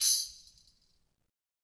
Tamb1-Hit_v1_rr2_Sum.wav